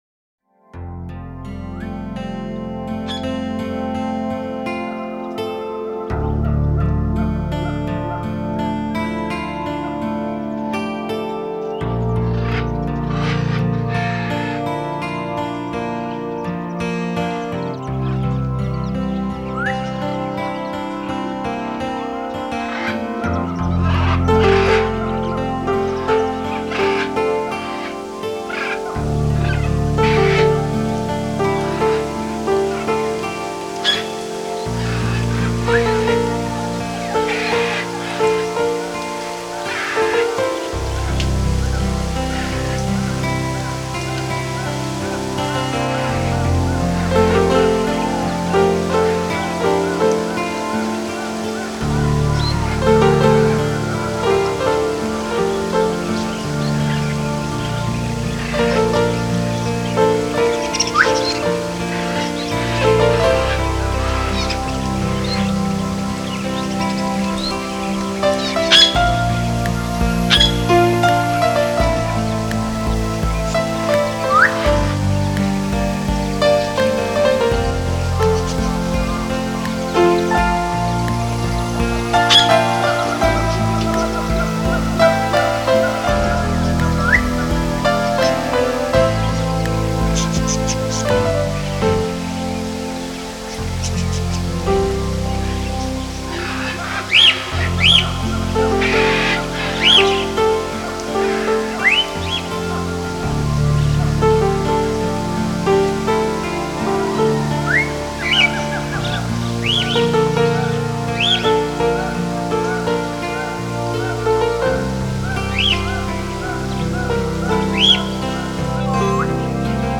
3D spatial surround sound "Music of nature"
3D Spatial Sounds